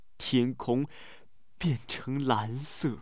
sad